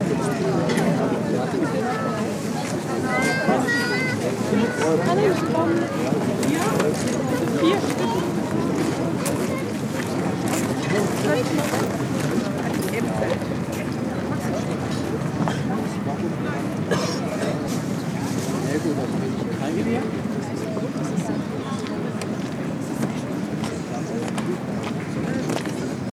Bar Sound Effects MP3 Download Free - Quick Sounds